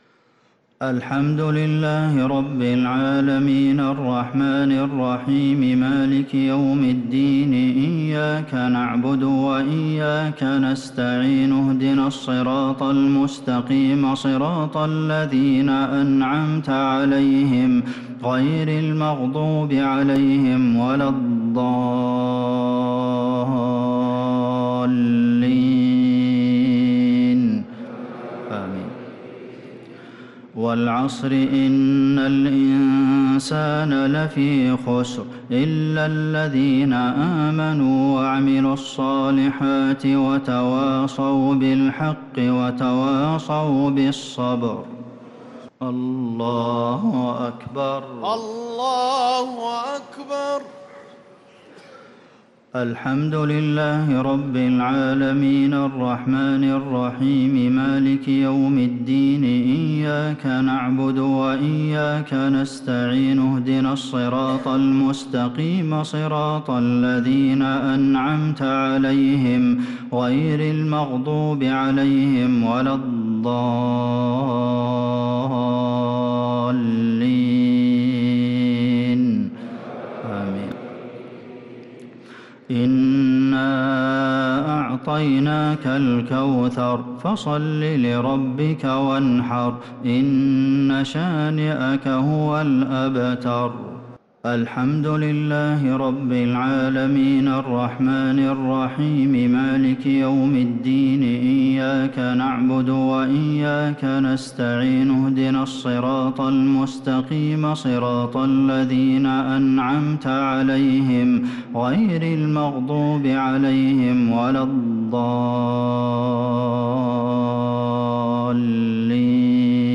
الشفع و الوتر ليلة 12 رمضان 1446هـ | Witr 12th night Ramadan 1446H > تراويح الحرم النبوي عام 1446 🕌 > التراويح - تلاوات الحرمين